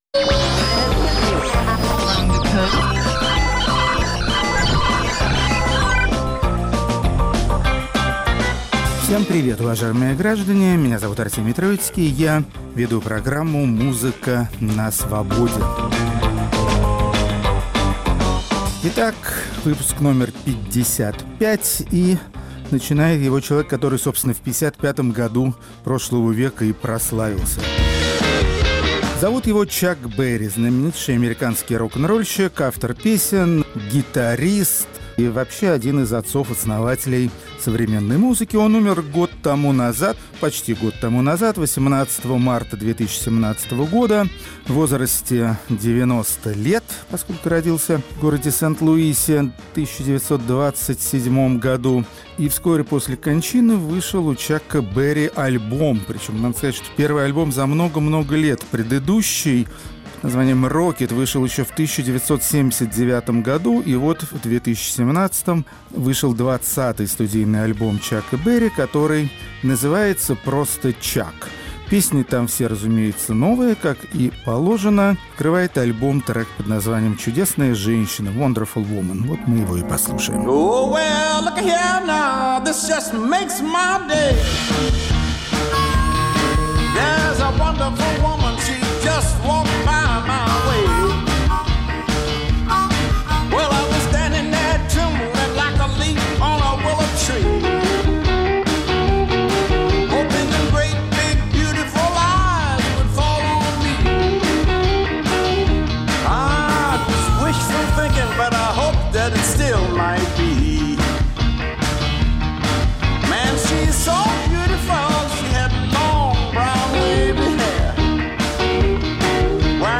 Хедлайнеры пятьдесят пятого выпуска программы "Музыка на Свободе" – исполнители психоделических рок-композиций.